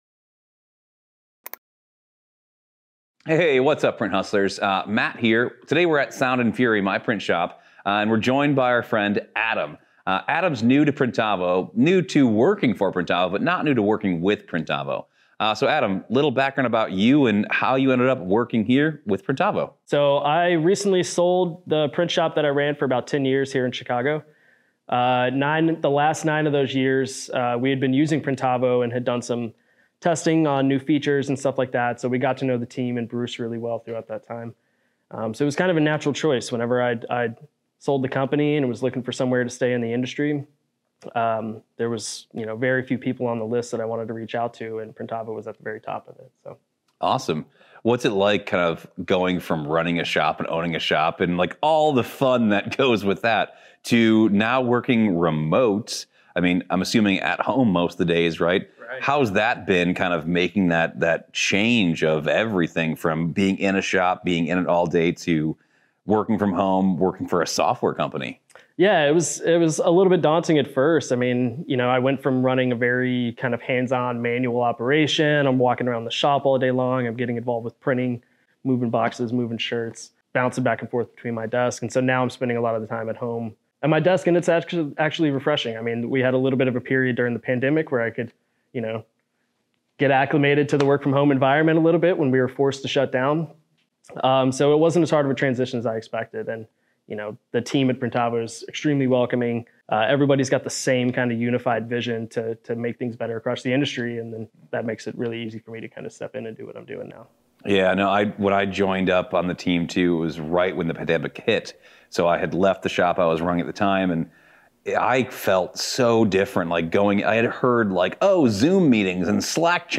In their Shop Owners on Shop Owners conversation